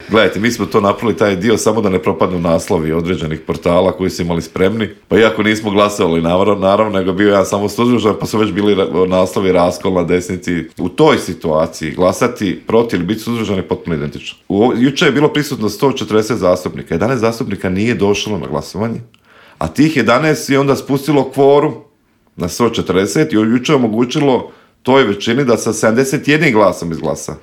Samo su neka od pitanja na koje smo u Intervjuu Media servisa odgovore potražili od saborskog zastupnika iz Domovinskog pokreta Marija Radića koji je najavio veliki politički skup stranke 23. ožujka u Zagrebu.